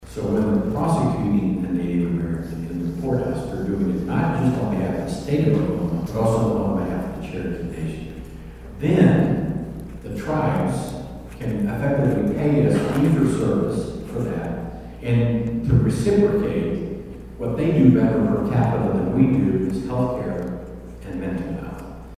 State AG Drummond Speaks to Bartlesville Rotary Club
Speaking in Bartlesville on Monday, Drummond told Rotary Club members that Oklahoma once had more than 12,000 licensed grow facilities, far exceeding what the market required—and feeding the black market.